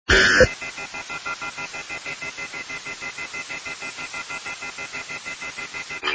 fx phone alert
71ckmfphonefx.mp3